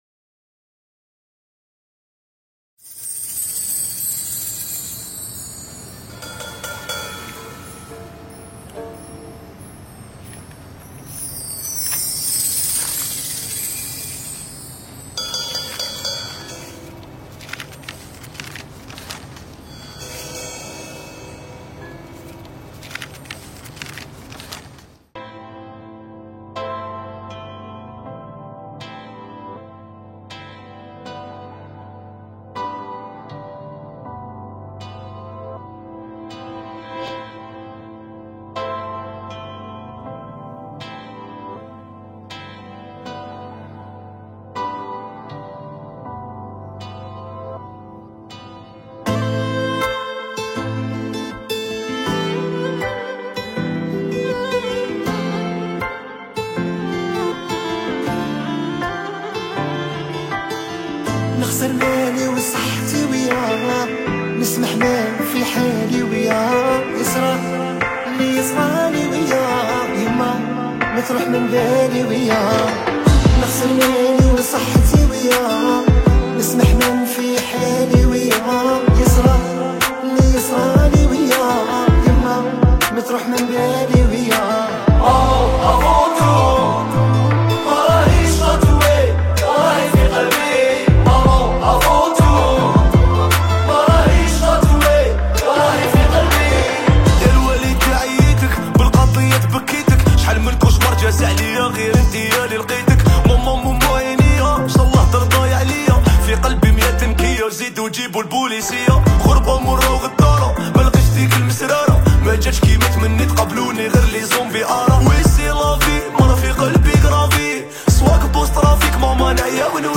اغاني الراي استماع songالنسخة الأصلية MP3